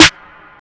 Snare (CAROUSEL).wav